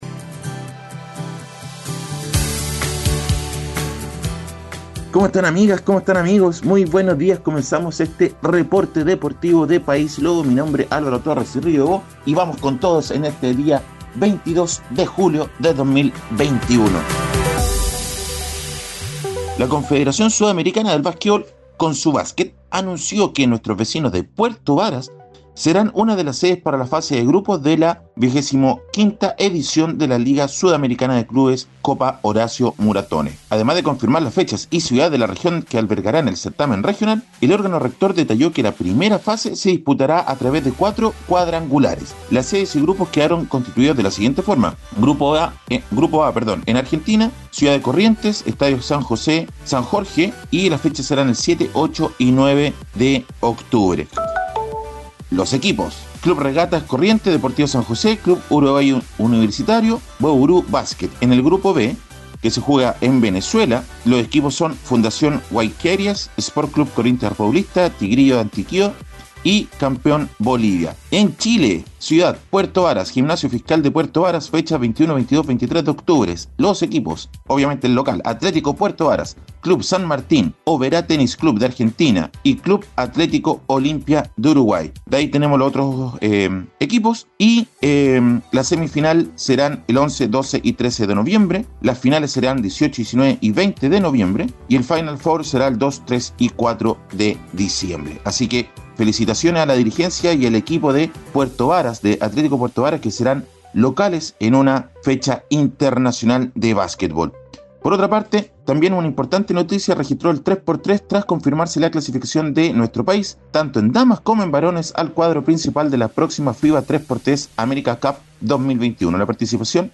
Reporte Deportivo ▶ 22 de julio 2021